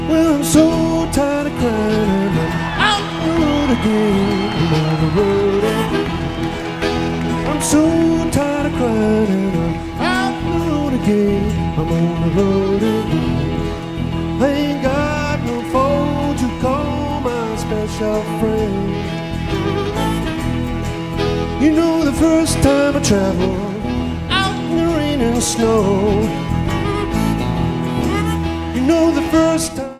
Excellent sound quality.